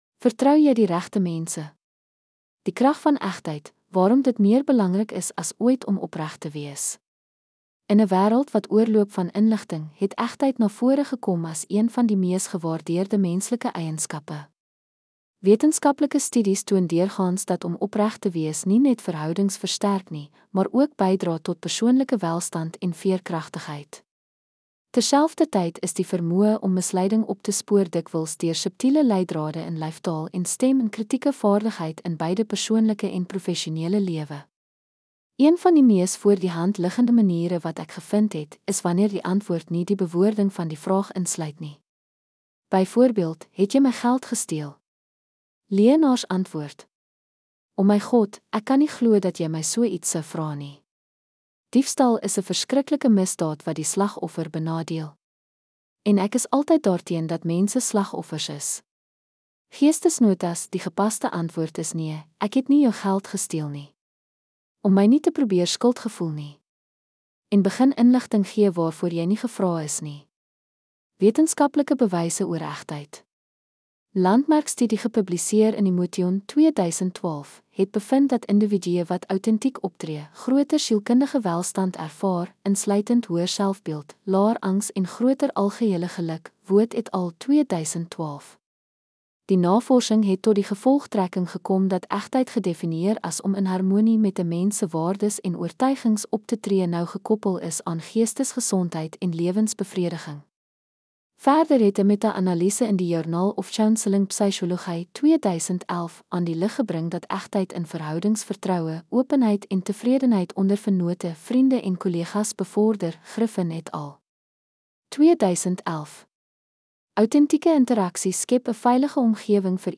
Welkom by vandag se episode-'n solo-verkenning na een van die lewe se mees gevolglike vrae: Hoe weet jy werklik of iemand vertrou kan word?